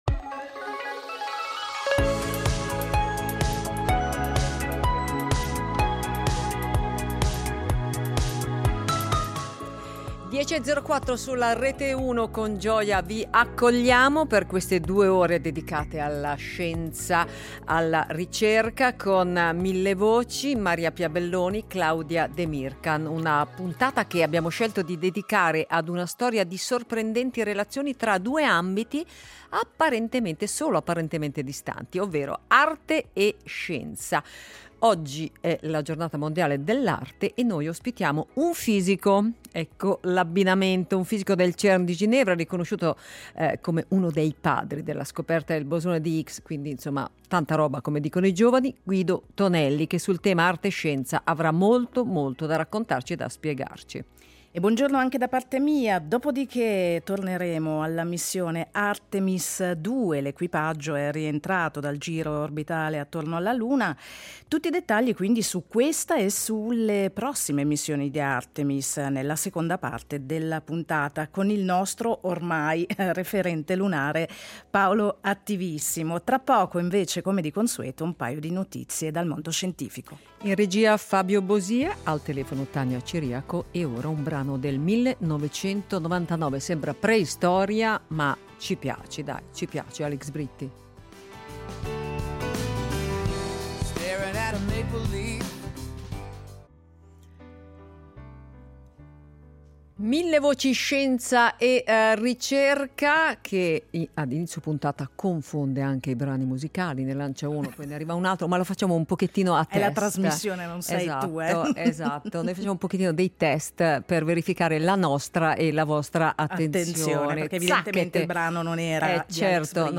Ospite: Guido Emilio Tonelli , fisico al CERN, divulgatore scientifico e saggista. Ci parla di scienza e arte e naturalmente delle ultime novità al CERN di Ginevra, dove è riconosciuto come uno dei padri della scoperta del bosone di Higgs.